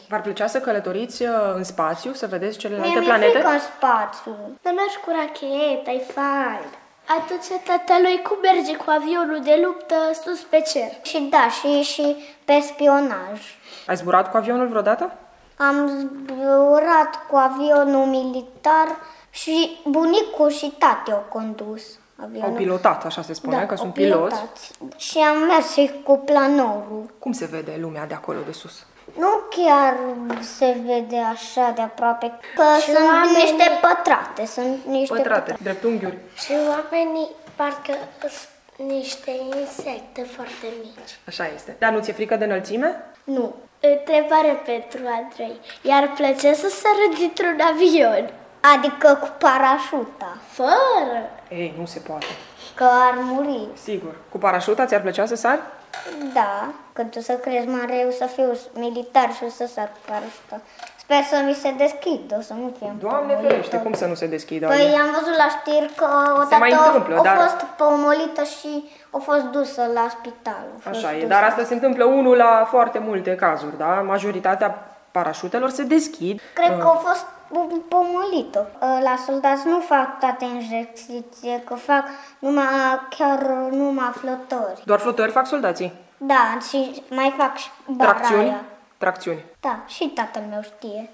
Copiii de la grădinița „Dumbrava minunată” din Tg. Mureș visează să devină astronauți, parașutiști sau piloți de avioane. Mulți dintre ei au zburat deja chiar și cu un planor și ne spun cum se vede lumea, privită de sus.